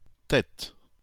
The Têt (French: [tɛt]